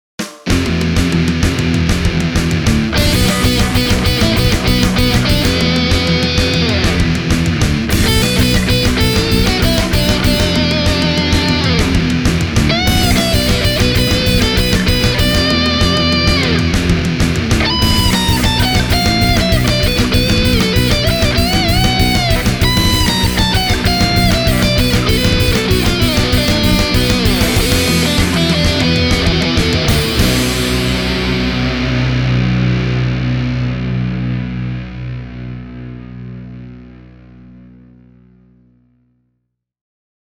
ESP:n LTD SCT-607B suorastaan rakastaa rankkaa riffailua ja kaikenlaista kieliakrobatiaa.
Pitkän mensuurin ja aktiivimikityksen tuoma selkeys helpottaa huomattavasti myös hyvin rankkojen särösoundien päällekkäin kasaamista, ilman että kokonaiskuva puurottaisi ongelmallisesti.